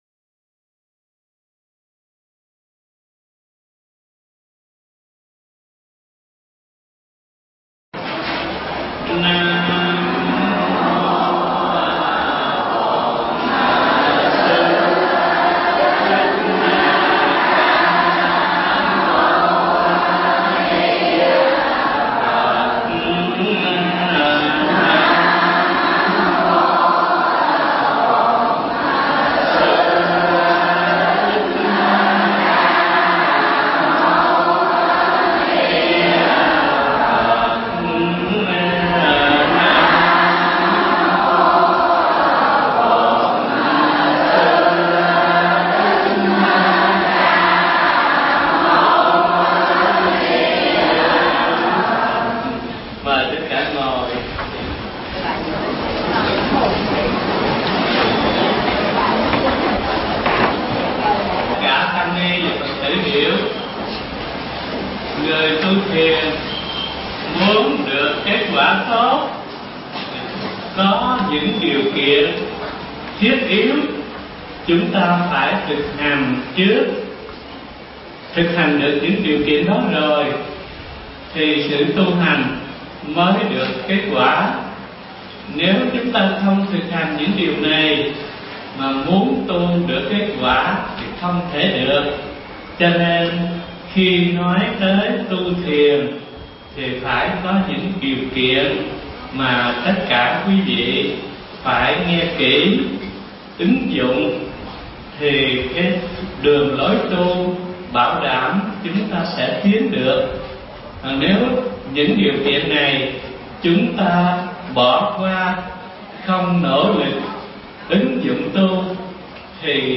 Mp3 Pháp Thoại Thiền Căn Bản 4 – Phần Chánh Tông – Cụ Duyên – Hòa Tượng Thích Thanh Từ giảng tại chùa Ấn Quang từ ngày 24 tháng 10 năm 1998